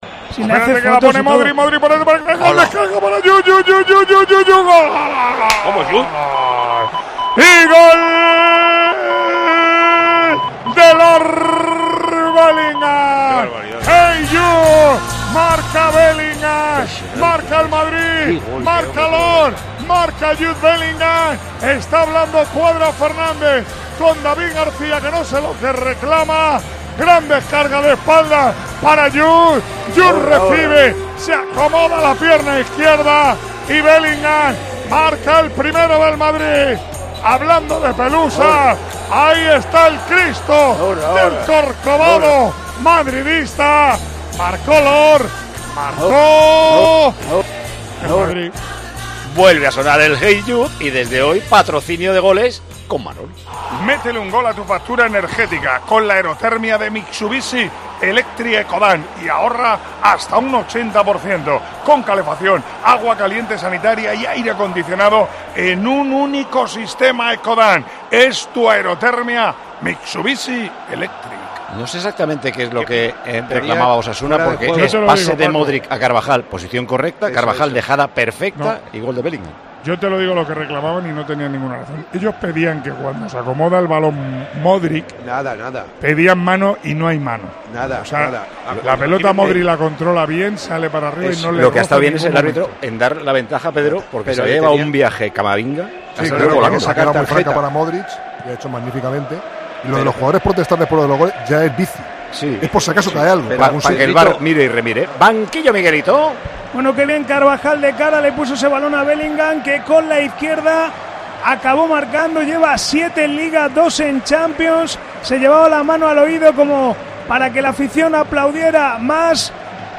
ASÍ TE HEMOS CONTADO EN TIEMPO DE JUEGO LA VICTORIA DEL REAL MADRID ANTE OSASUNA